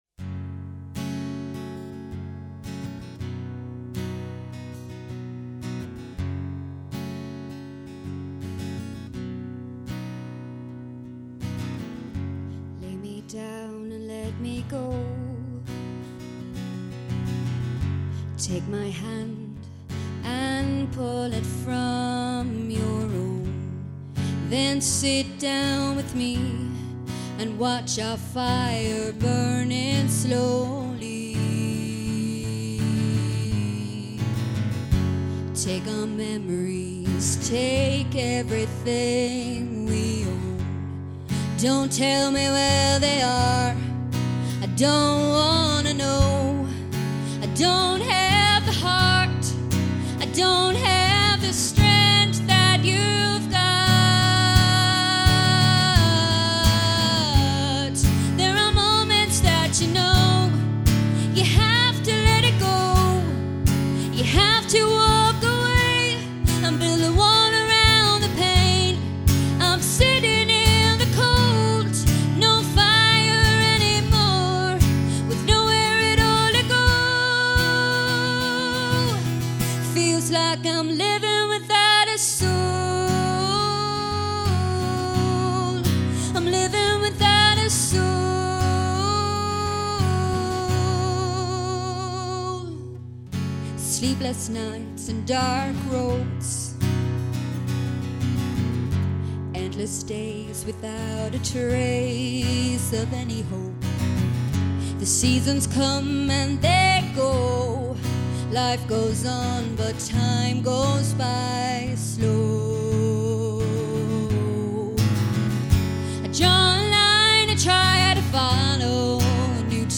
Each of the acts recorded live versions of their entries.
Country Pop Singer-Songwriter and guitarist